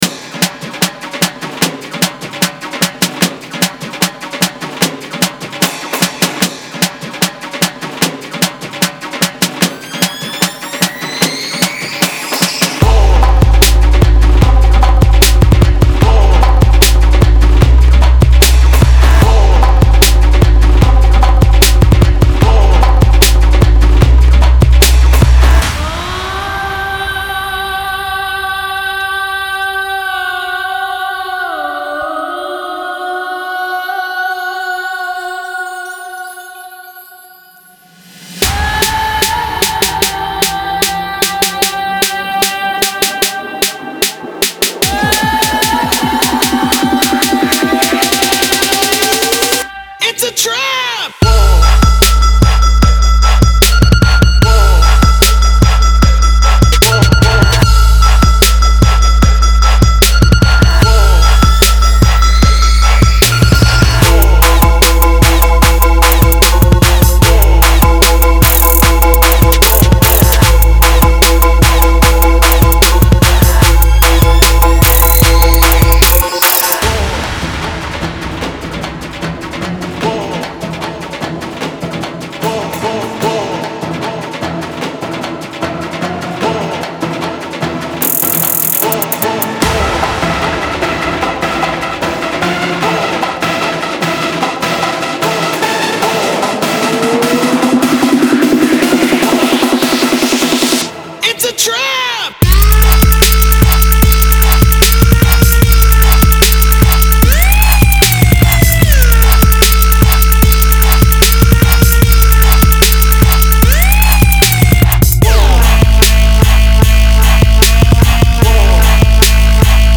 • Жанр: Trap